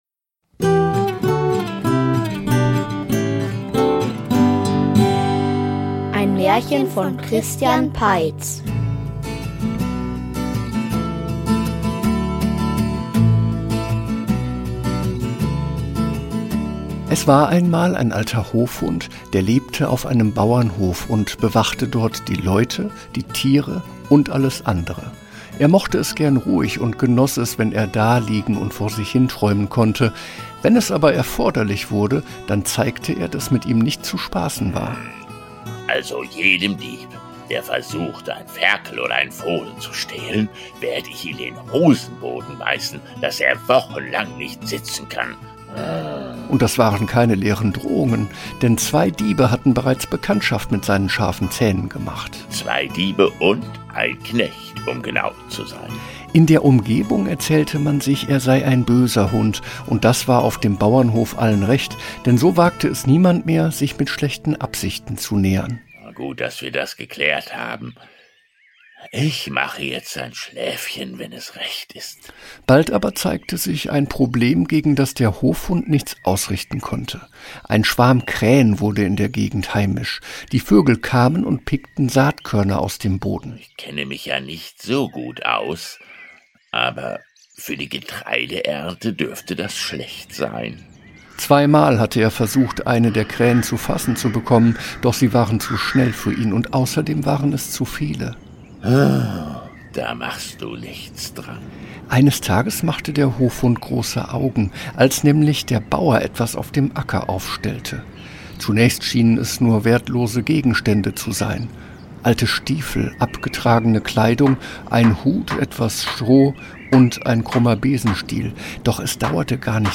Wohin die Krähen fliegen --- Märchenhörspiel #58 ~ Märchen-Hörspiele Podcast